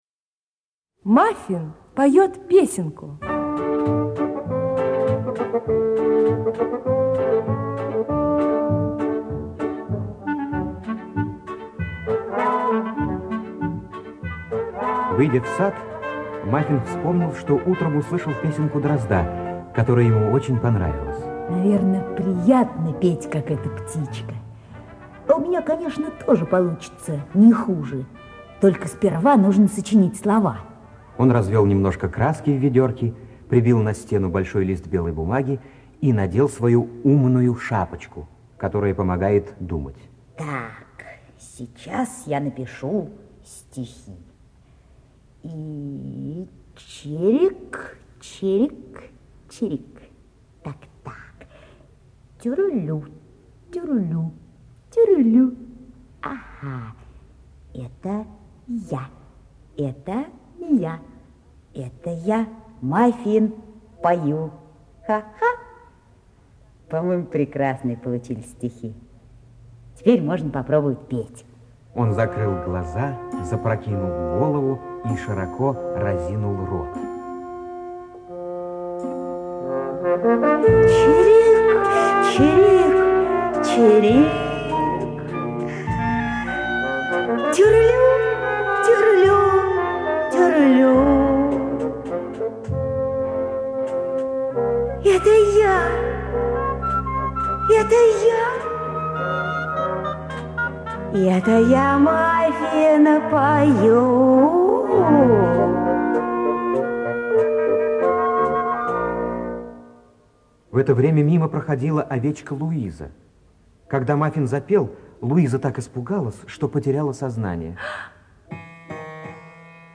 Мафин поёт песенку — аудиосказка Хогарт - слушать онлайн